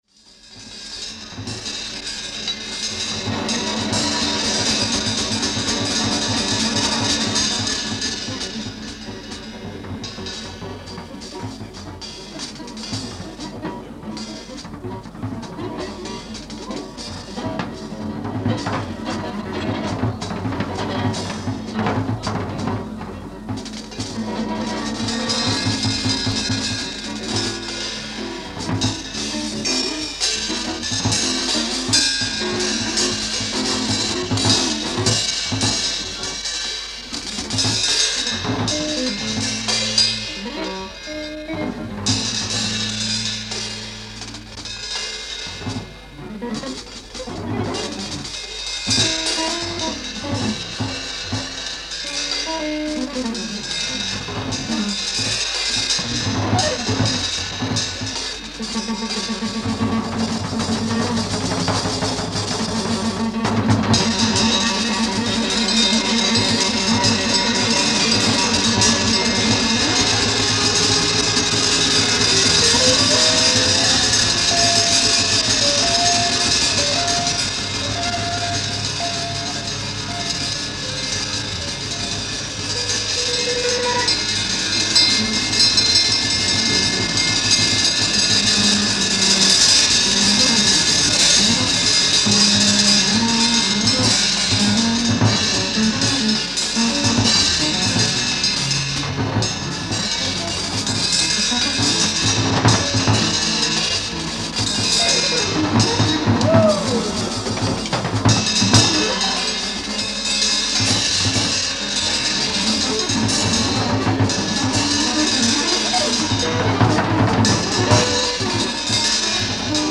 1-3:スタジオ・セッション・ベルリン、ドイル 11/09/1968
※試聴用に実際より音質を落としています。